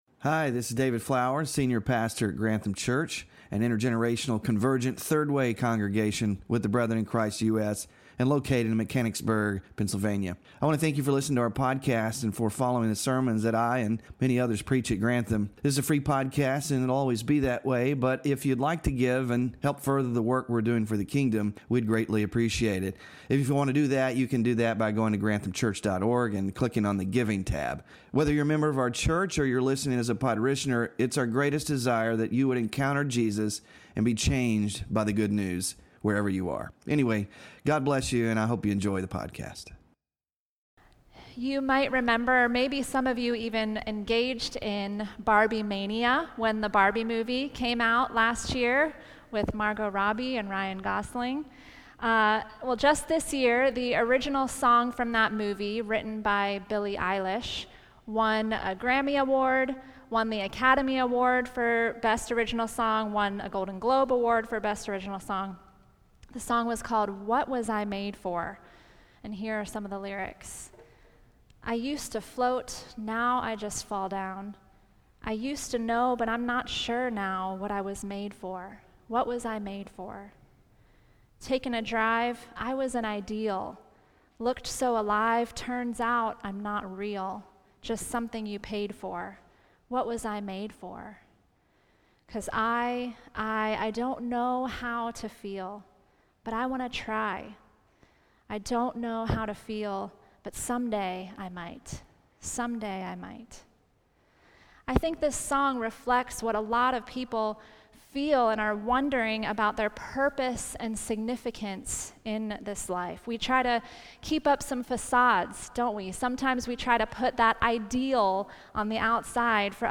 WORSHIP RESOURCES Glory of God Wk 1 Sermon Slides Small Group Discussion Questions (5-5-24)